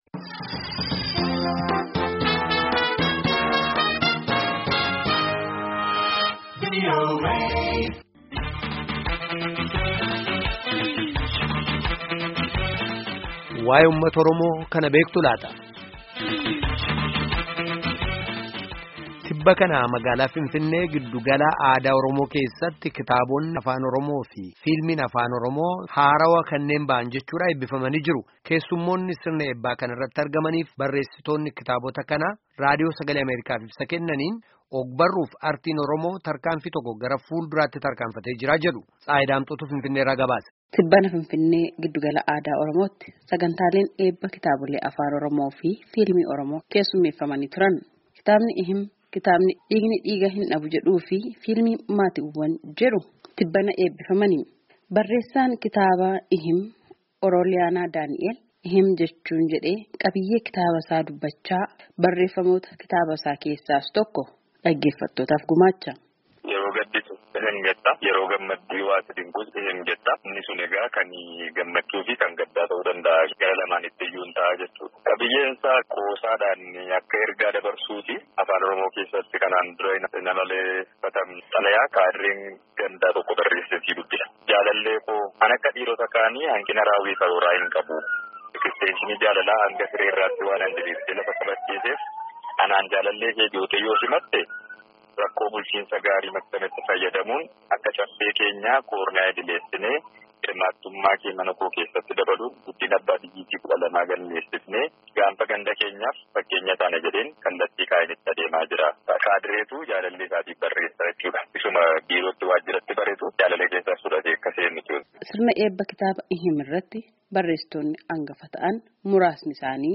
Keessummoonni sirna eebbaa kana irratti argamanii fi barreessitoonni kitaabota haaraa lameenii, Raadiyoo Sagalee Ameerikaaf yaada kennaniin Artii fi ogbarruun Oromoo tarkanfii tokko gara fuul-duratti tarkaanfachuu isaa dubbatu.